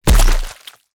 body_hit_finisher_52.wav